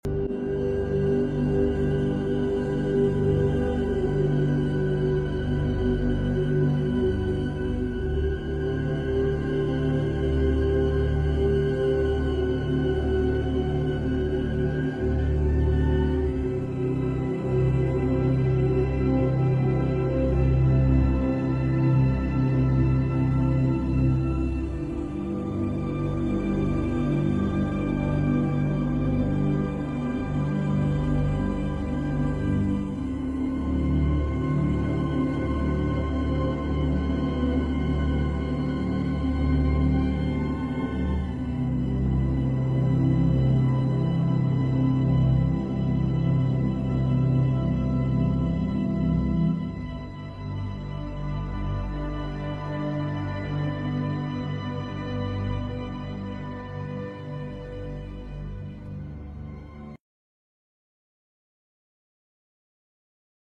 Mother cat protects her kittens